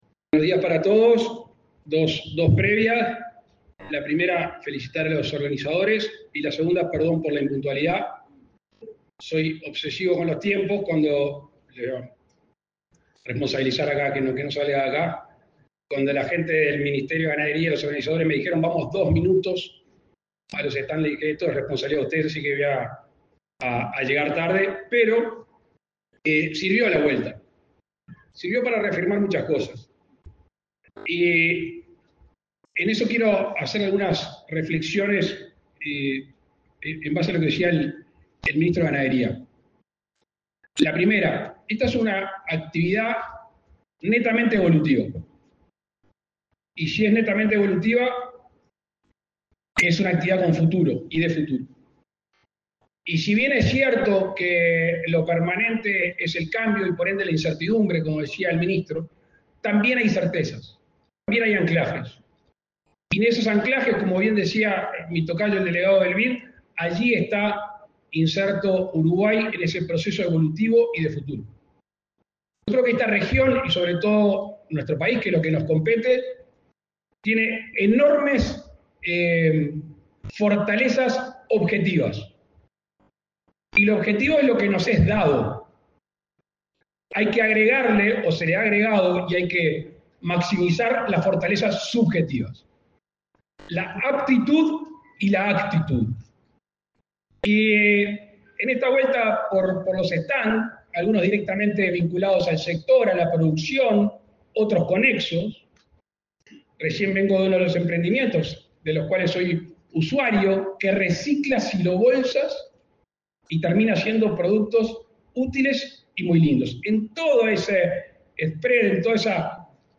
Palabras del presidente de la República, Luis Lacalle Pou
Palabras del presidente de la República, Luis Lacalle Pou 05/02/2025 Compartir Facebook X Copiar enlace WhatsApp LinkedIn El presidente de la República, Luis Lacalle Pou, participó, este 5 de febrero, en la apertura del foro Uruguay Sostenible en la exposición Agro en Punta, que se realizó en el Centro de Convenciones de Punta del Este.